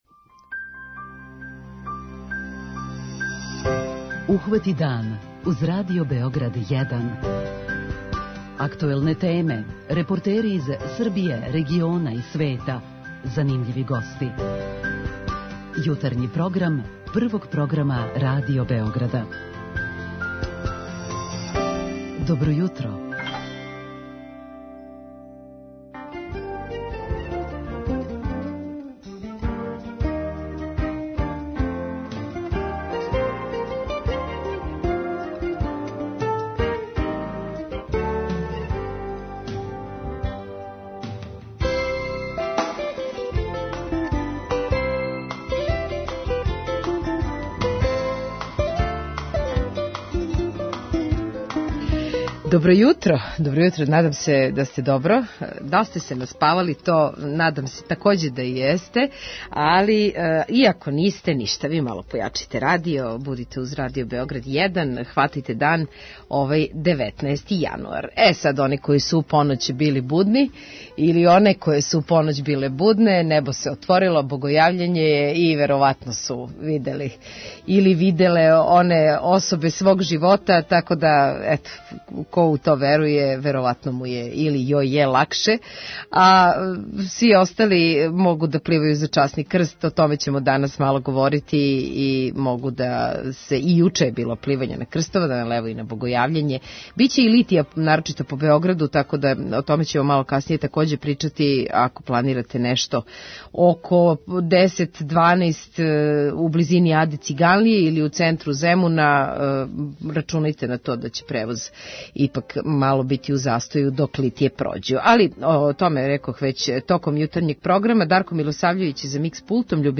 У програм ћемо укључити и надлежне из Сектора за ванредне ситуације. преузми : 37.78 MB Ухвати дан Autor: Група аутора Јутарњи програм Радио Београда 1!